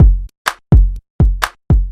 描述：鼓的循环排序
标签： 桶环
声道立体声